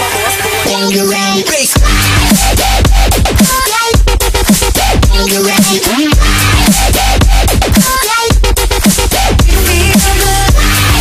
DUBSTEP
Category: Sound FX   Right: Personal